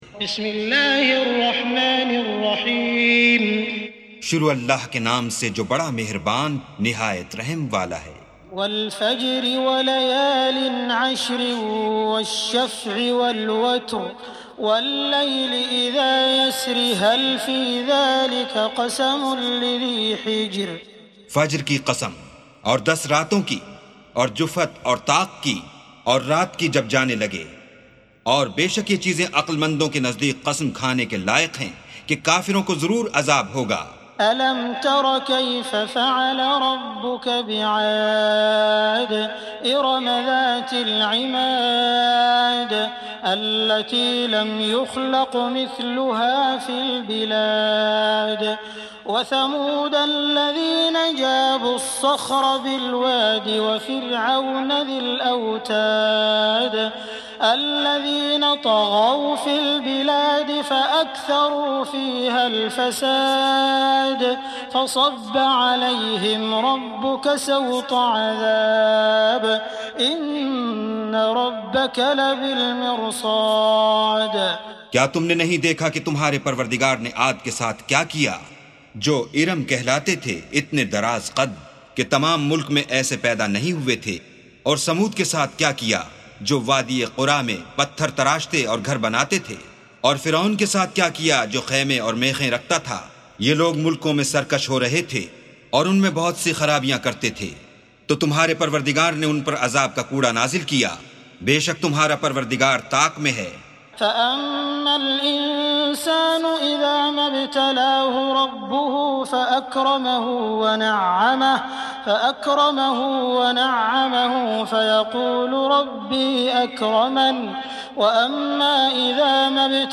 استمع أو حمل سُورَةُ الفَجۡرِ بصوت الشيخ السديس والشريم مترجم إلى الاردو بجودة عالية MP3.
سُورَةُ الفَجۡرِ بصوت الشيخ السديس والشريم مترجم إلى الاردو